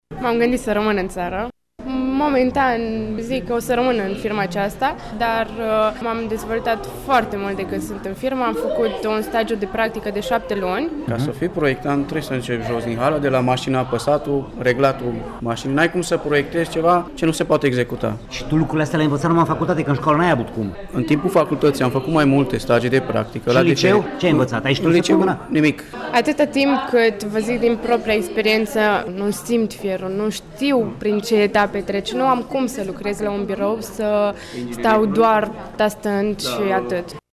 Cu toate acestea, ei recunosc că în liceu nu au făcurt nimic practic, iar acum, la o facultate tehnică, acest lucru este resimțit ca un mare handicap:
stiri-16-mai-cali-vox.mp3